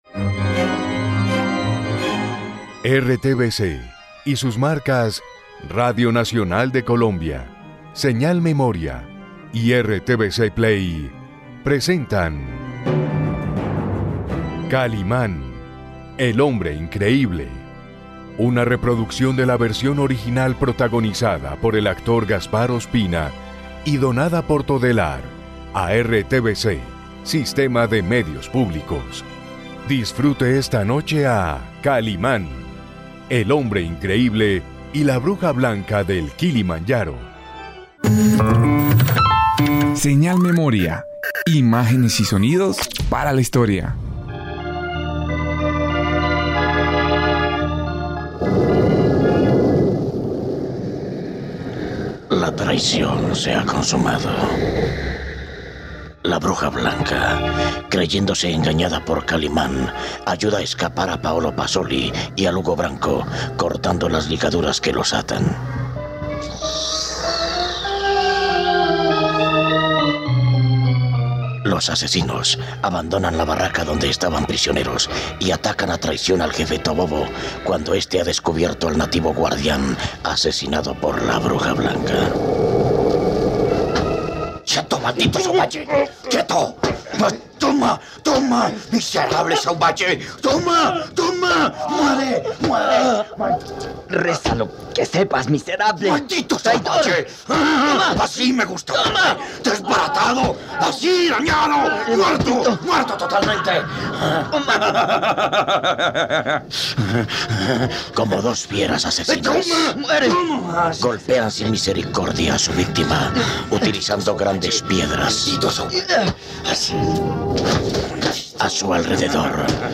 Esta radionovela ésta en su recta final...